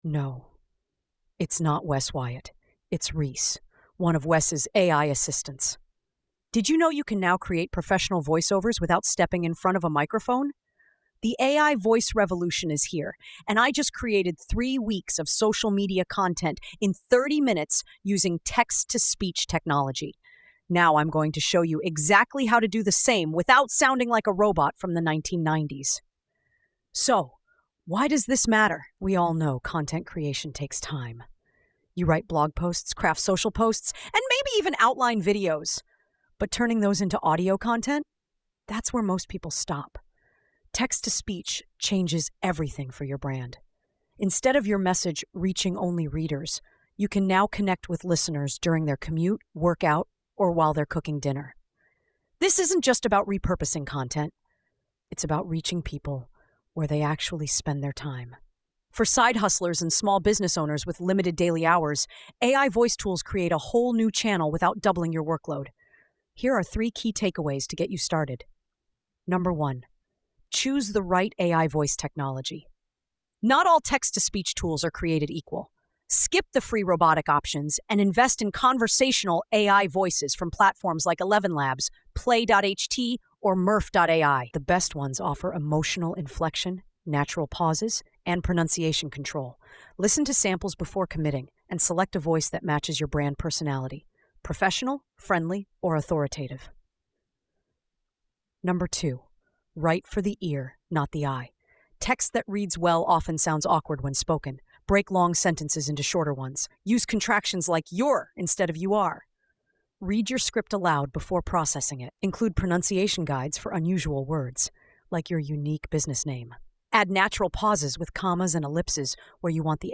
The speaker, an AI assistant named Reece, elucidates the transformative capabilities of contemporary text-to-speech systems that have emerged in recent years.